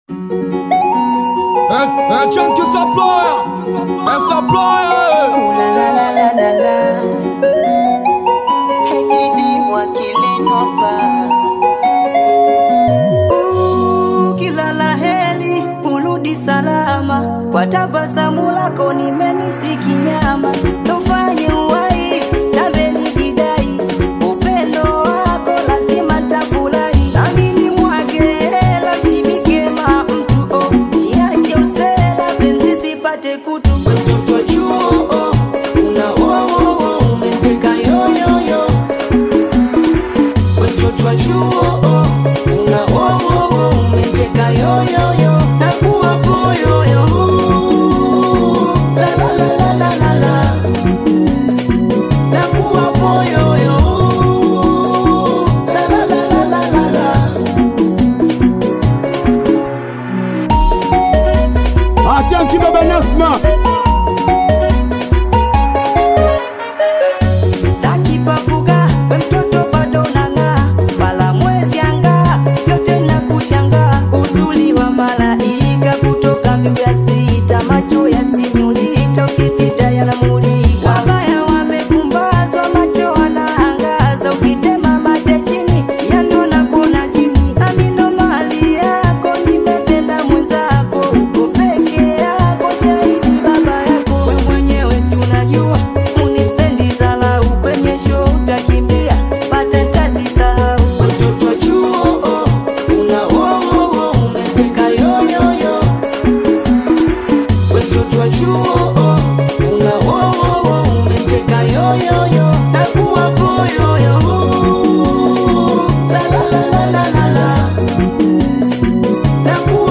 BITI SINGELI
SINGELI BEAT